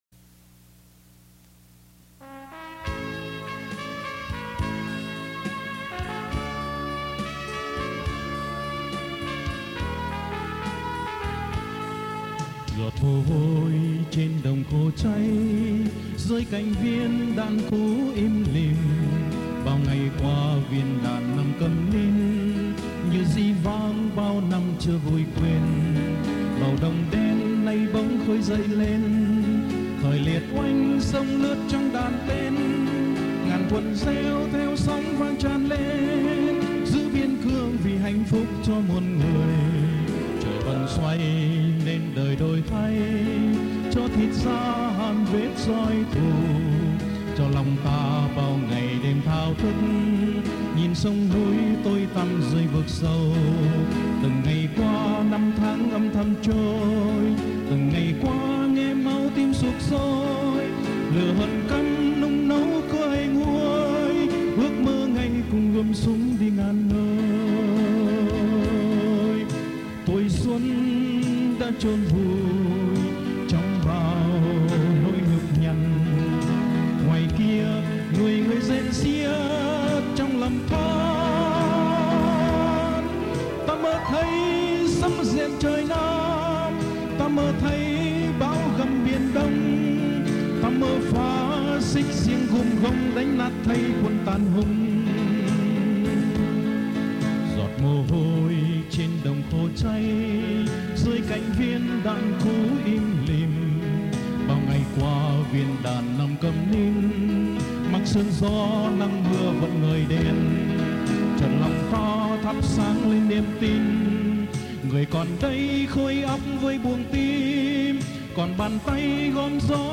Tù Khúc